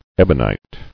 [eb·on·ite]